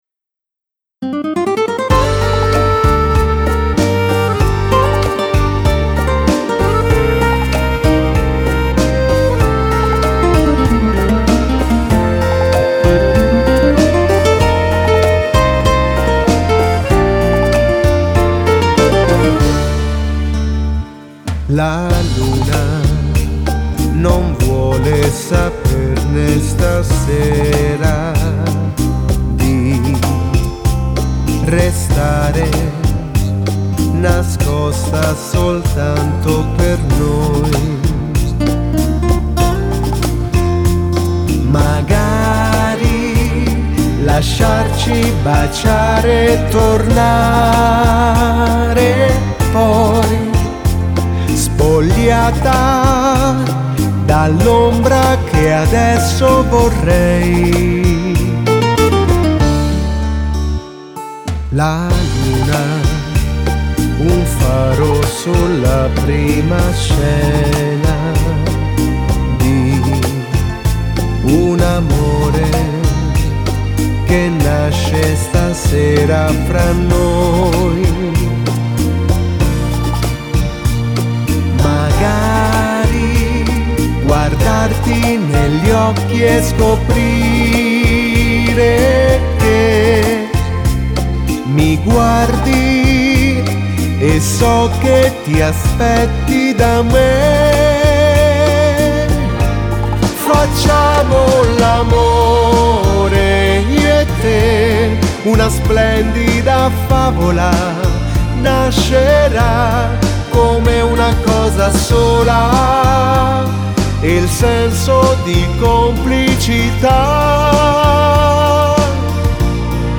Rumba
Bellissima e romantica rumba